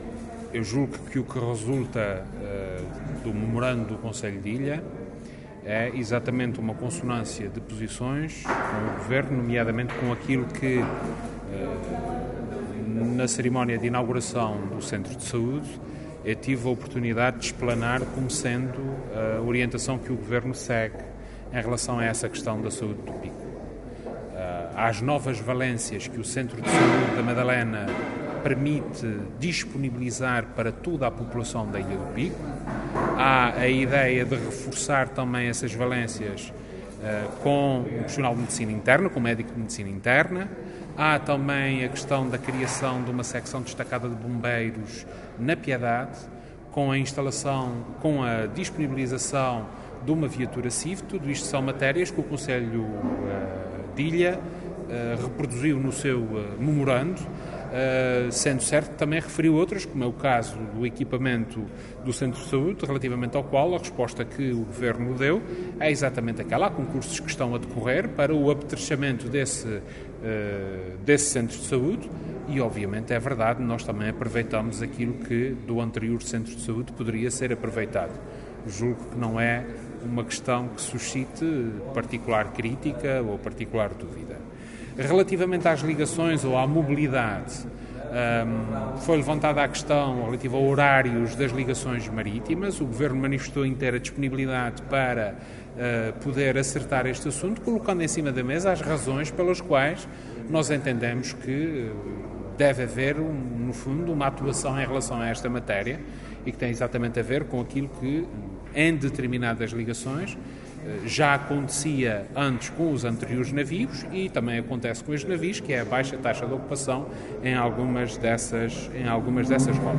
“Há compreensão em relação a estas matérias e há um trabalho que vai ser feito de aprofundamento e de análise destas questões”, referiu o Presidente do Governo, em declarações aos jornalistas.